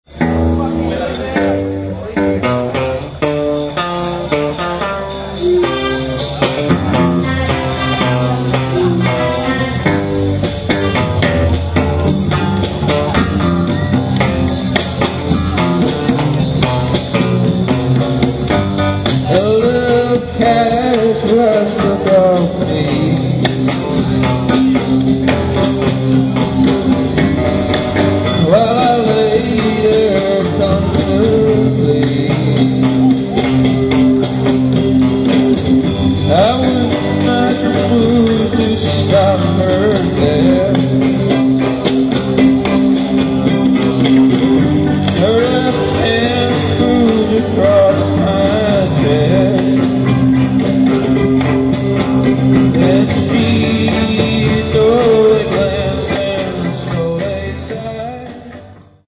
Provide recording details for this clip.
live in Cuba